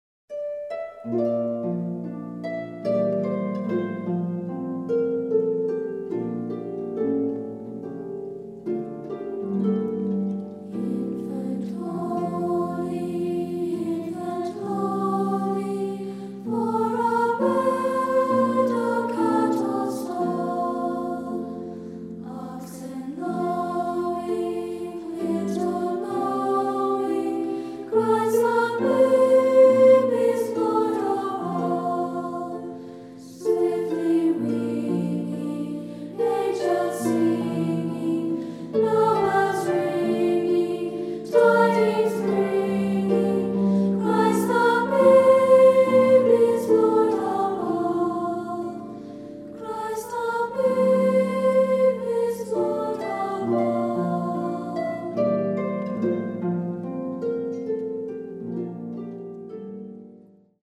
SA, Christmas
Polish Carol